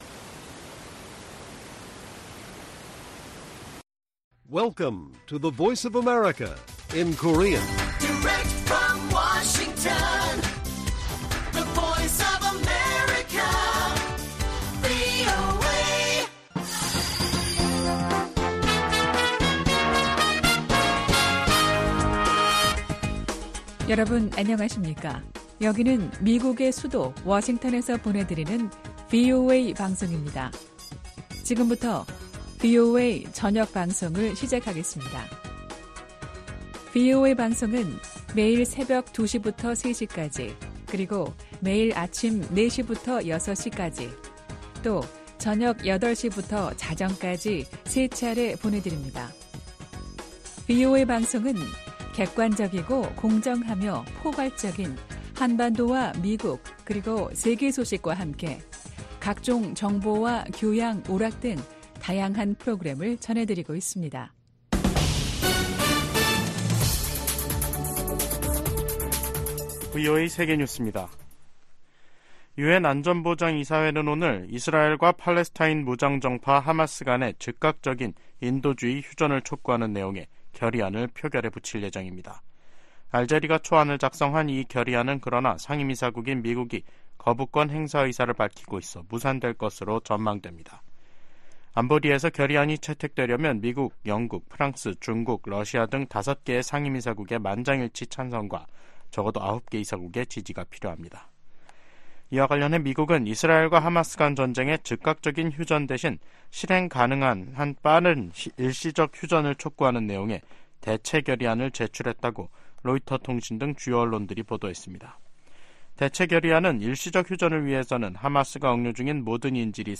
VOA 한국어 간판 뉴스 프로그램 '뉴스 투데이', 2024년 2월 20일 1부 방송입니다. 블라디미르 푸틴 러시아 대통령이 김정은 북한 국무위원장에게 러시아산 승용차를 선물했습니다. 미 국무부가 역내 긴장 고조는 미국 탓이라는 북한의 주장을 일축하고, 미국과 동맹의 연합훈련은 합법적이이라고 강조했습니다. 미 국무부는 유엔 북한인권조사위원회(COI) 최종 보고서 발표 10주년을 맞아 북한 정권에 인권 문제 해결을 촉구했습니다.